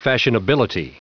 Prononciation audio / Fichier audio de FASHIONABILITY en anglais
Prononciation du mot : fashionability